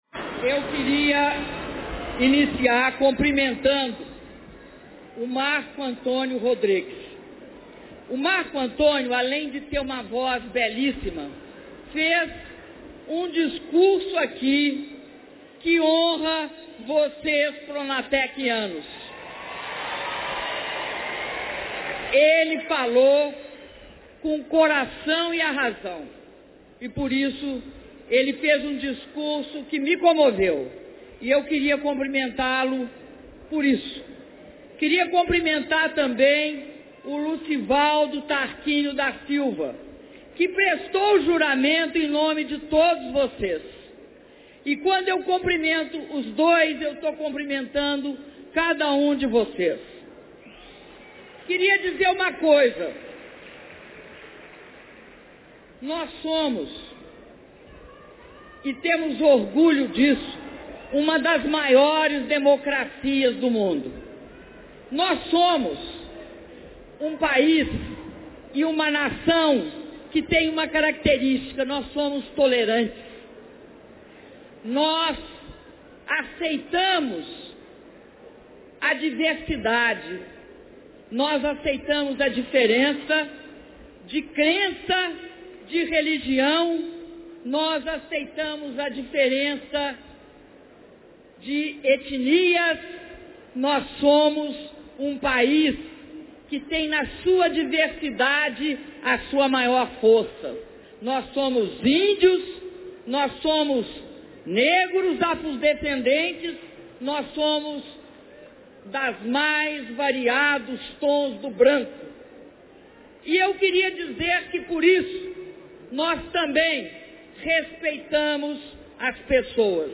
Áudio do discurso da Presidenta na formatura de 4.500 alunos do Pronatec - Natal/RN (31min47s)
Discurso da Presidenta Dilma Rousseff na cerimônia de formatura de 4.500 alunos do Pronatec e de inauguração de três novos campi do IFRN: Ceará-Mirim, Canguaretama e São Paulo do Potengi - Natal/RN